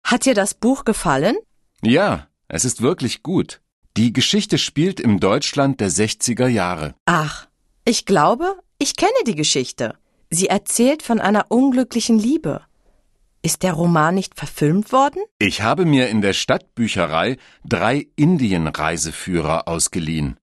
Un peu de conversation - La lecture